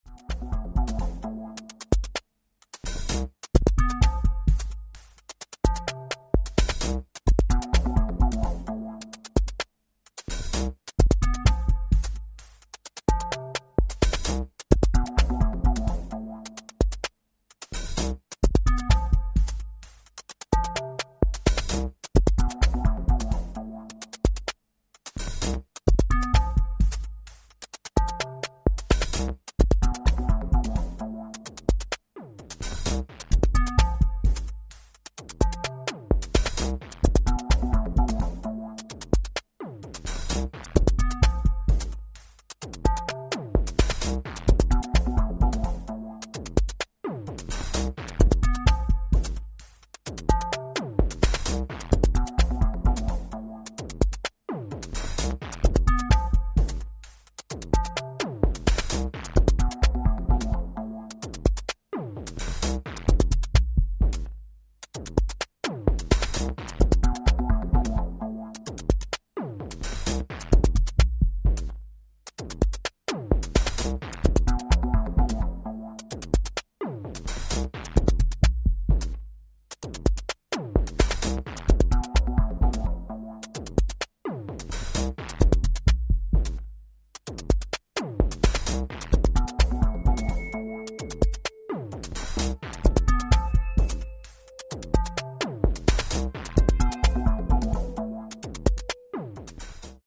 Darker electro stuff.